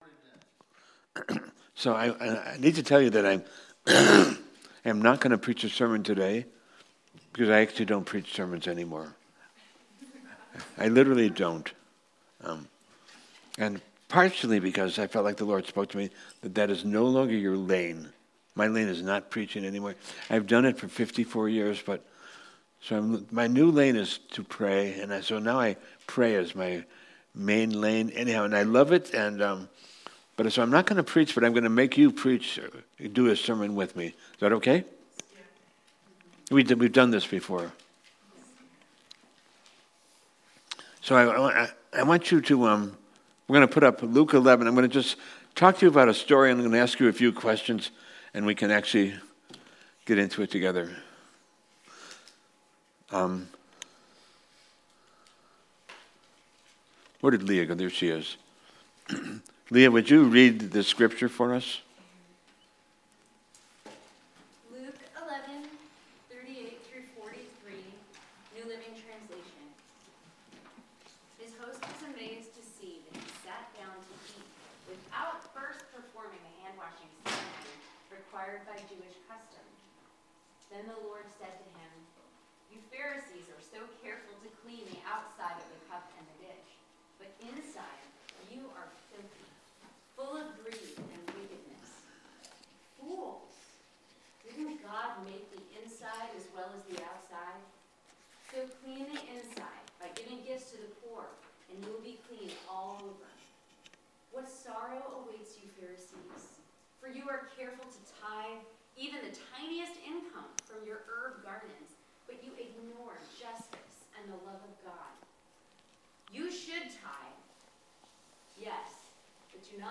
Bible Study on Mary and Martha - Grace Christian Fellowship
Sermons